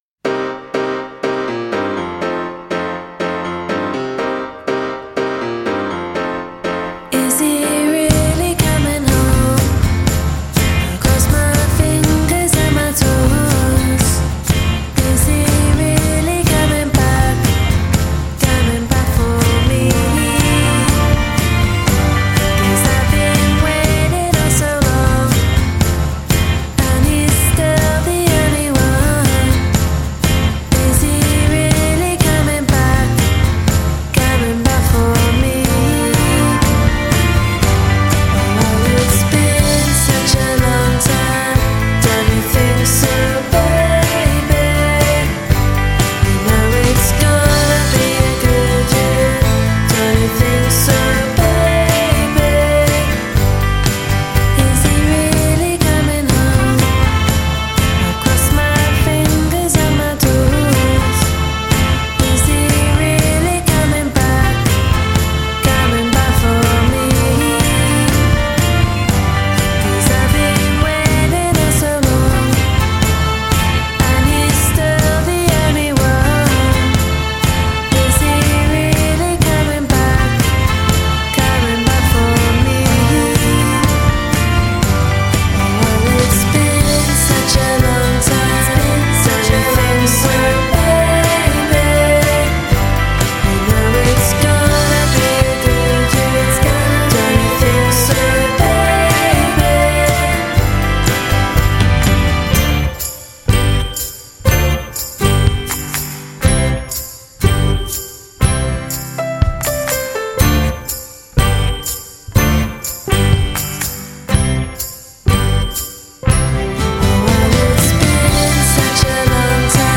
British pop band
60’s girl group sound
This stuff really does feel 1963.